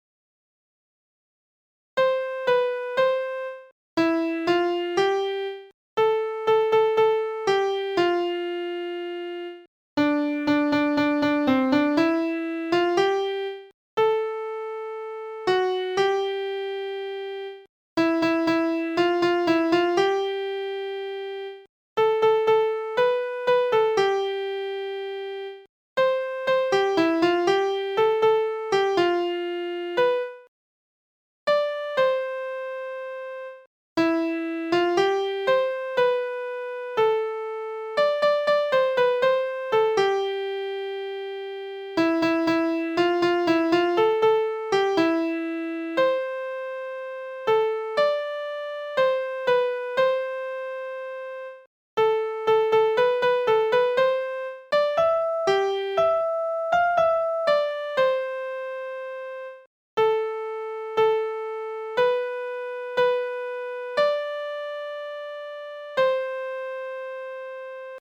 MP3file(ピアノメロデイーのみ)(1.31MB)